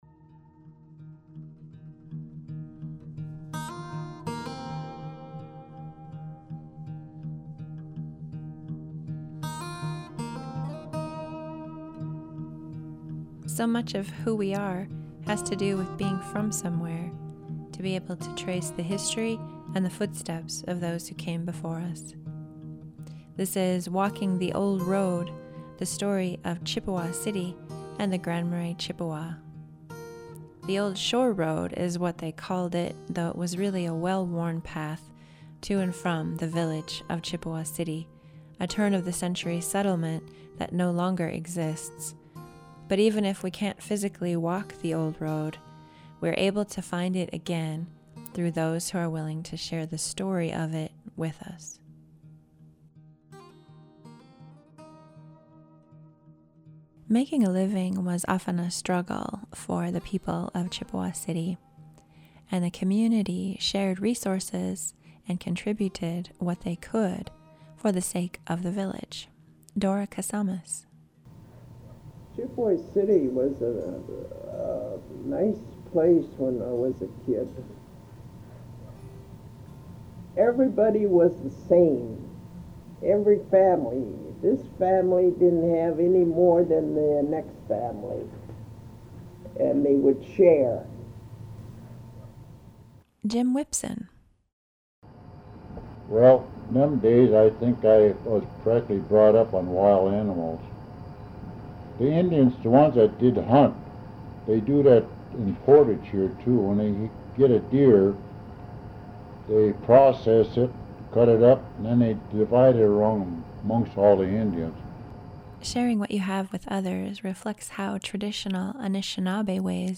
Photos for this series are courtesy of the Cook County Historical Society and portions of some achieved interviews courtesy of the Grand Portage National Monument.